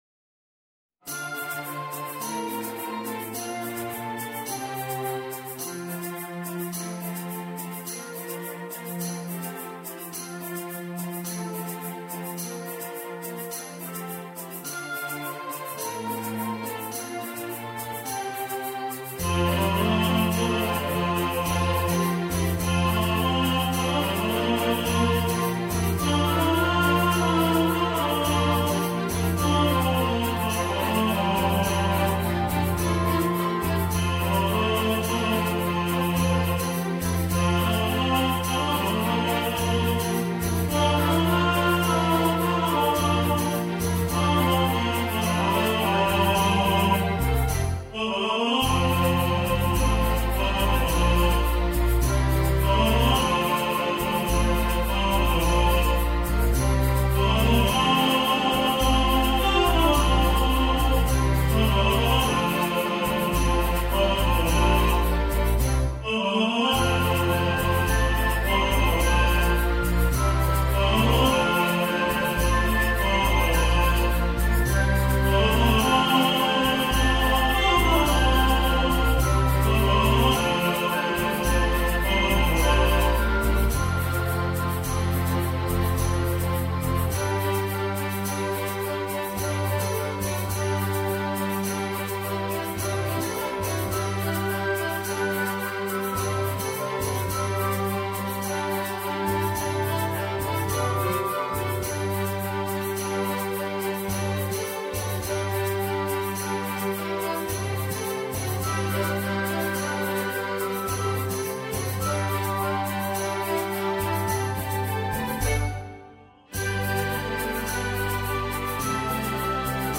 Road Goes On (Tenor Solos) | Ipswich Hospital Community Choir
Road-Goes-On-Tenor-Solos.mp3